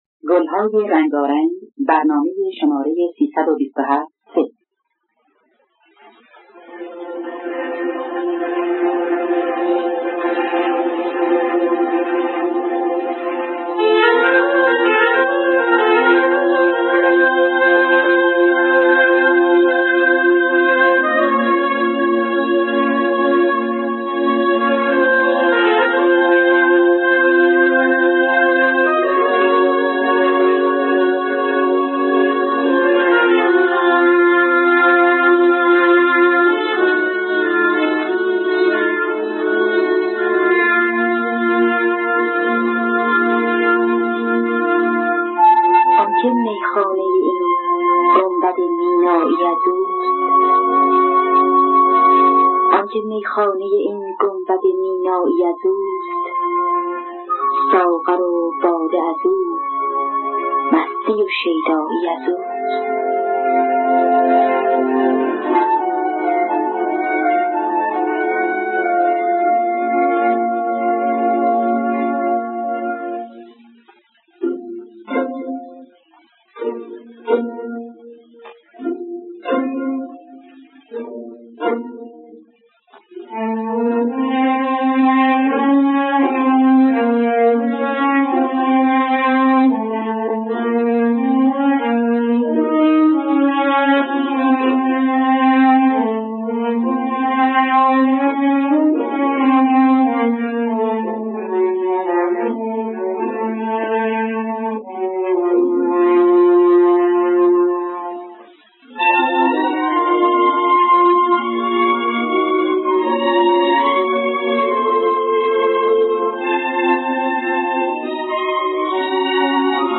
دانلود گلهای رنگارنگ ۳۲۷ث با صدای پوران، عبدالوهاب شهیدی در دستگاه همایون.
دانلود گلهای رنگارنگ ۳۲۷ث - آرشیو کامل برنامه‌های رادیو ایران